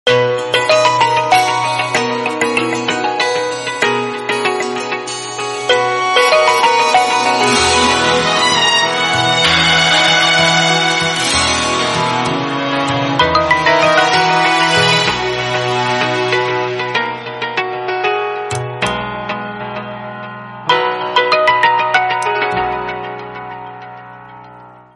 Ringtones Category: Top Ringtones